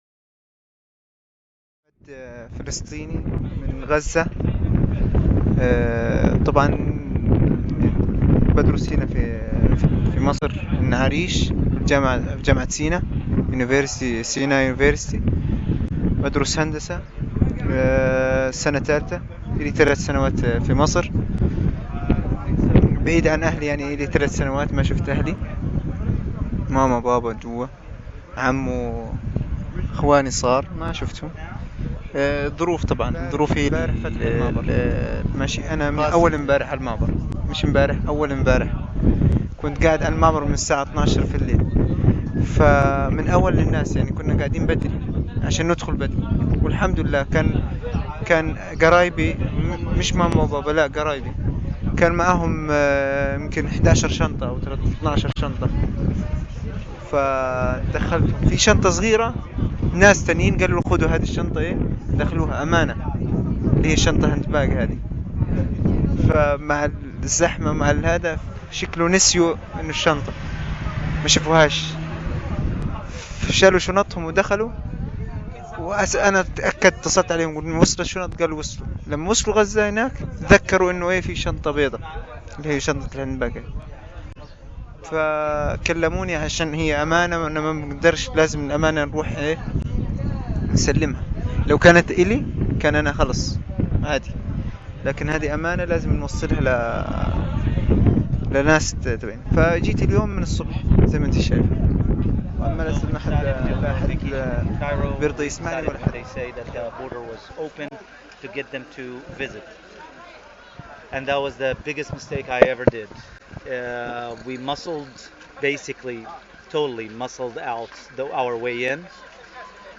I giorni 13-14-15 settembre 2013 il Laboratorio di Sociologia Visuale sarà presente con una installazione sonora al festival Free.Q presso il Museo di Arte Contemporanea di Villa Croce (Via Jacopo Ruffini 3, Genova).